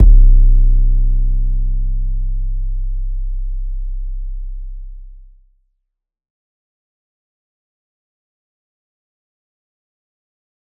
• 50 high-quality, hard-hitting 808 drum samples.
• A variety of tones from clean subs to gritty distortion.
Bombz-808-Sample-C1-2.mp3